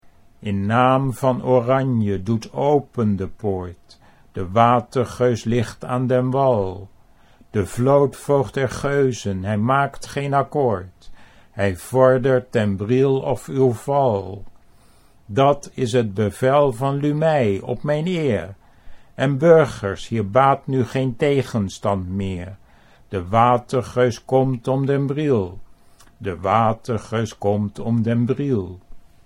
Reading the poem aloud, its rhythm came naturally, without special effort - but it's not how people in Holland normally speak.